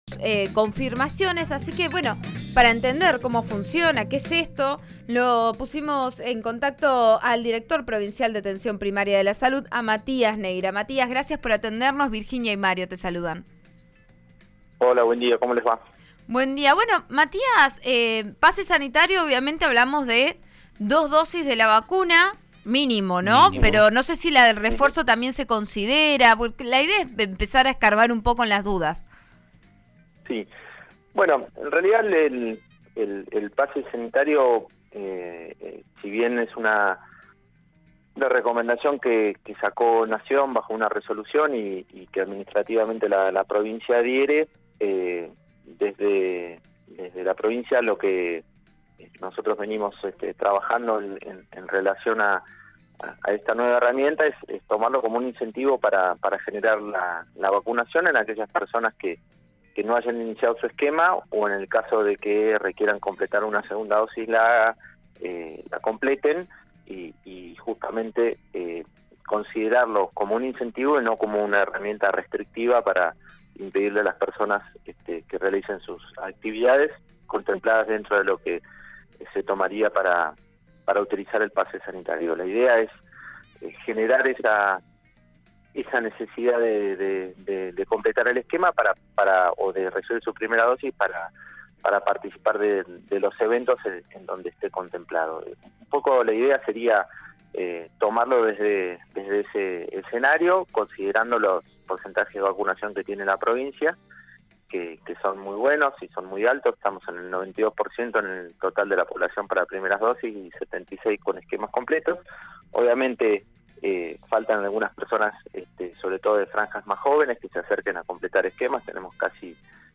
El director de Atención Primaria de la Salud, Matías Neira, indicó en declaraciones al programa Vos a Diario de RN RADIO (89.3) que son 50.000 personas las que no han completado el esquema de vacunación aún estando en condiciones de hacerlo y que la mayoría de ellas son jóvenes.